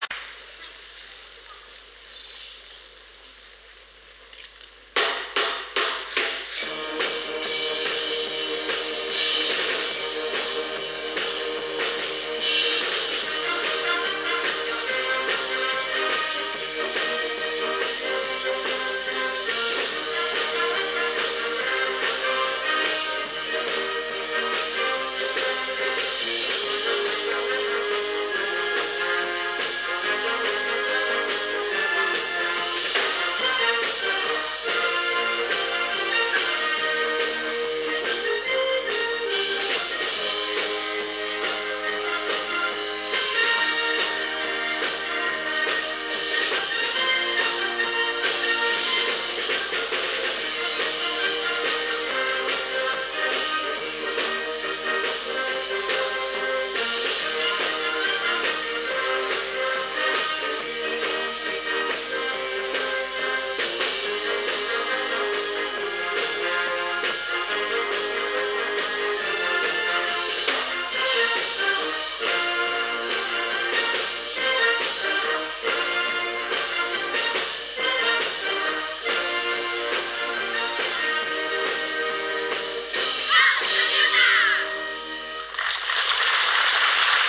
合奏を録音したMP3ファイルです。
録音は2000年の富岡六旗です。
演奏はこの年の当番校、明治大学です。